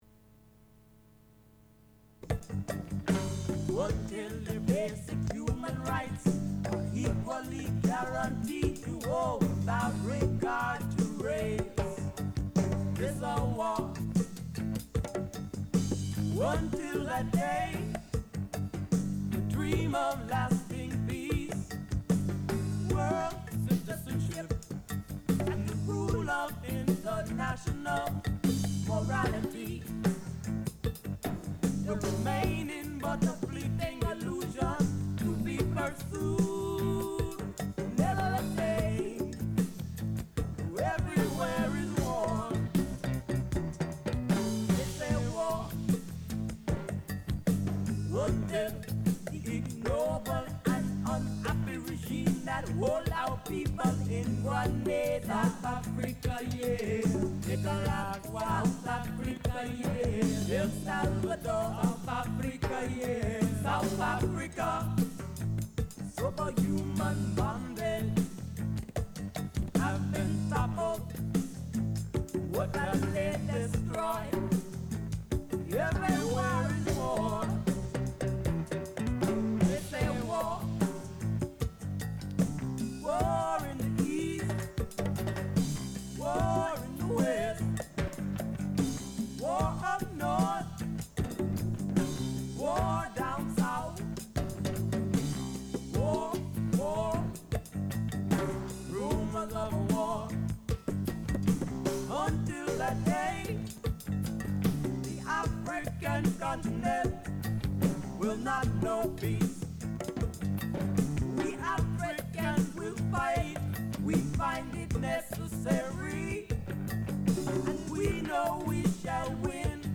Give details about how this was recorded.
This show was a special broadcast of Lesbian Power Authority featuring music and poetry from lesbians around the world.